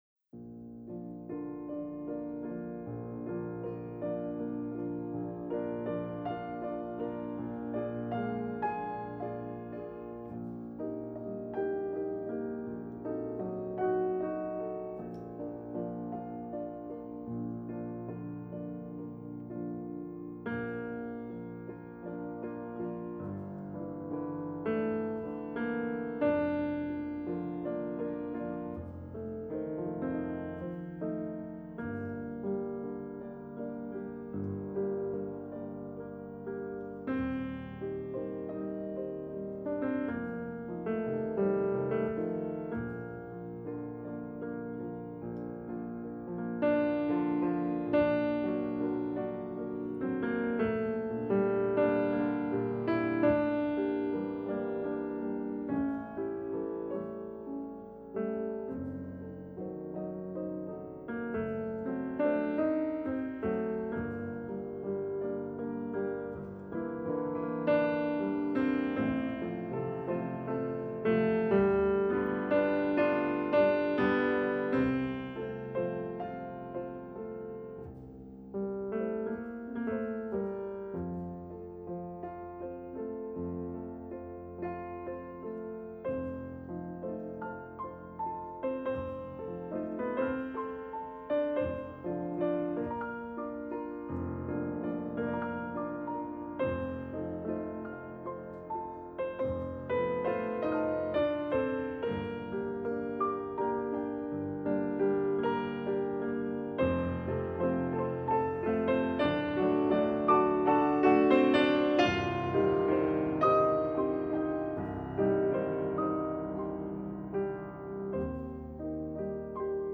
PISTES AUDIO PIANO :